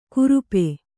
♪ kurupe